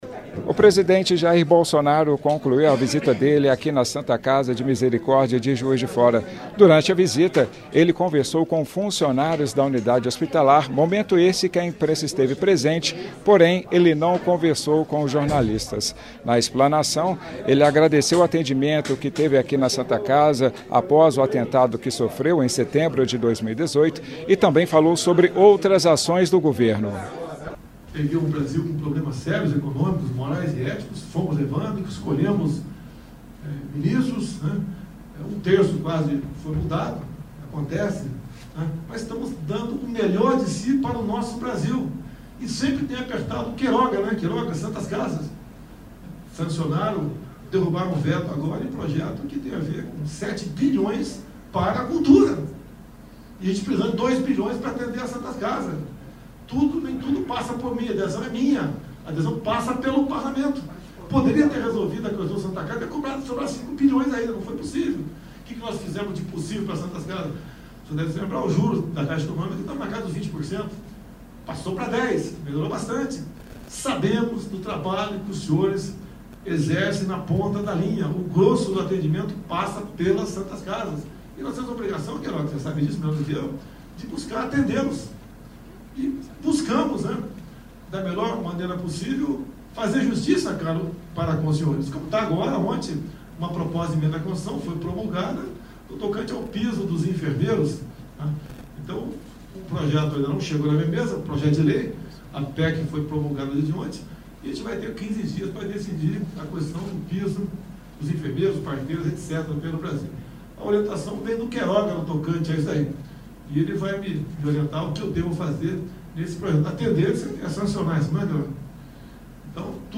Em seguida, o presidente visitou a Santa Casa de Misericórdia, onde discursou aos funcionários, falou sobre ações e dificuldades enfrentadas pelo governo e agradeceu o atendimento que recebeu em 2018.